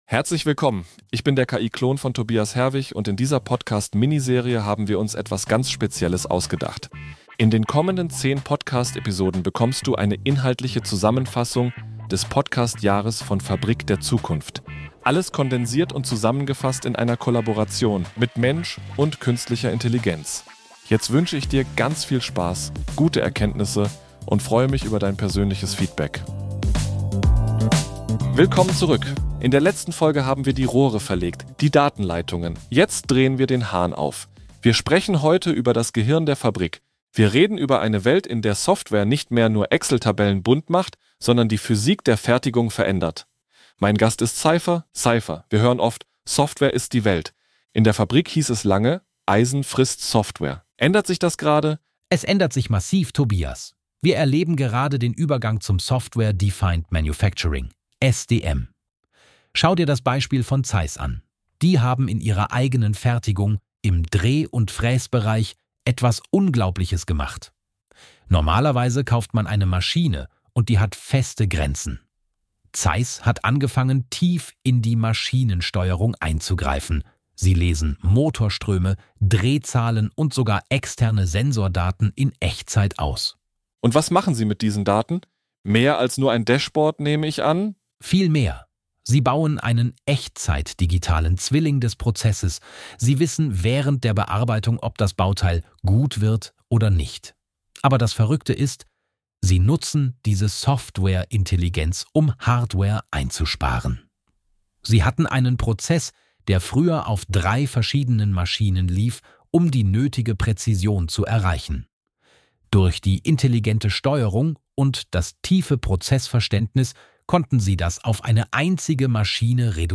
einem generativen KI-Agenten und Innovation-Scout für